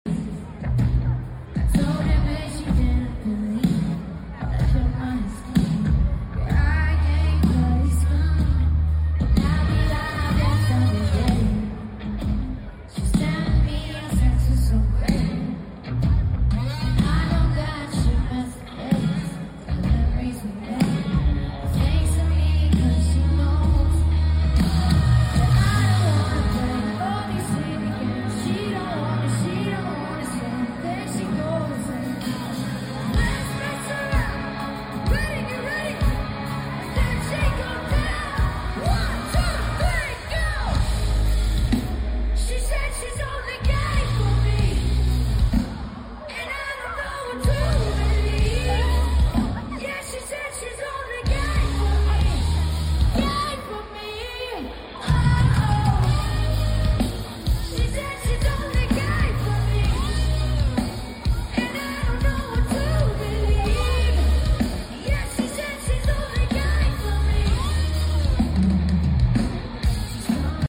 Never thought I would get to hear this song live